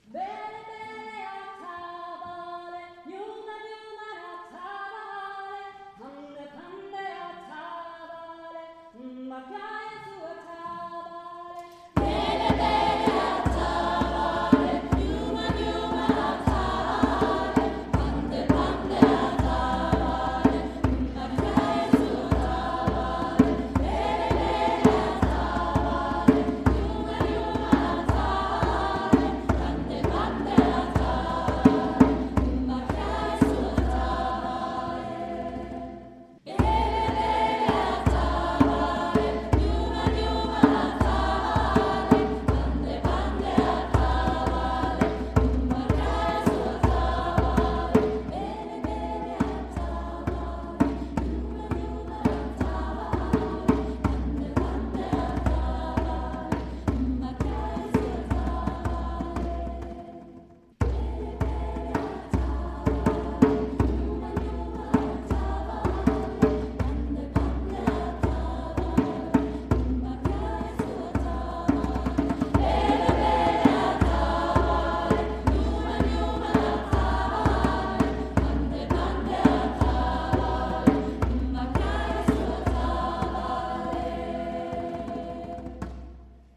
Unser Chor begleitete diese Messe mit neuen afrikanischen Gesängen die den einen oder anderen Kirchenbesucher zum "mitshaken" animierten.
Die afrikanischen Lieder aus dem Gottesdienst